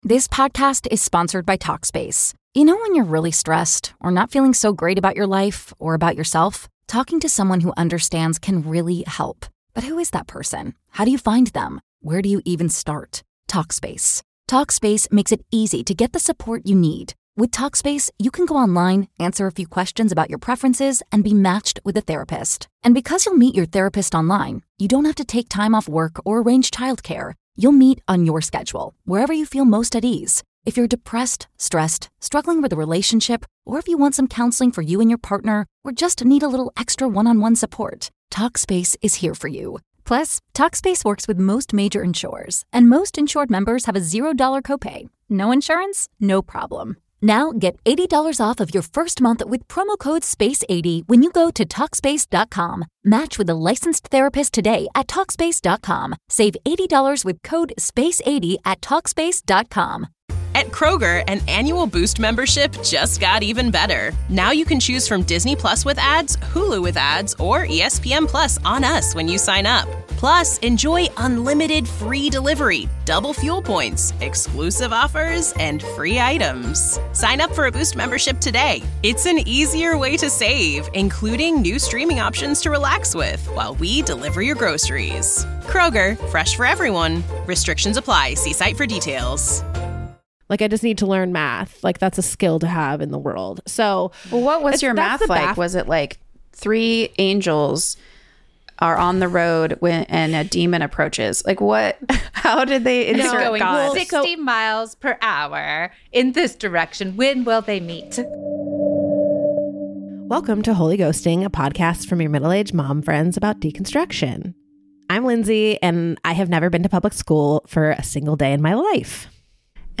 Our three hosts share all about their sheltered education from unschooling, home schooling and private education …so you know there are some pretty wild stories from Christian Campus life.